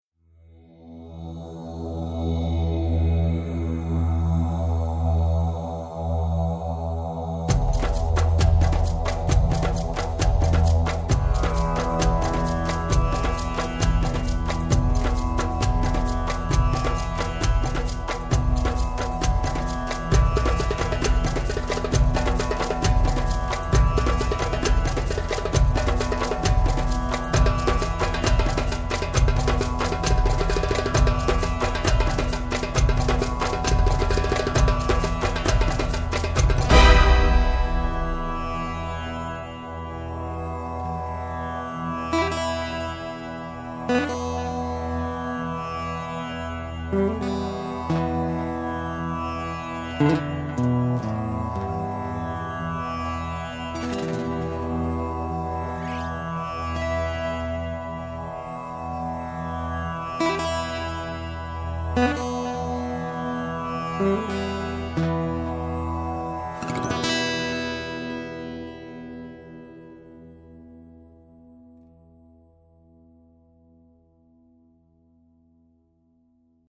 Texturas orquestales y ambientales electrónicas